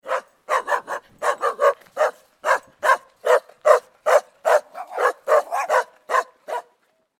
دانلود صدای سگ توله از ساعد نیوز با لینک مستقیم و کیفیت بالا
جلوه های صوتی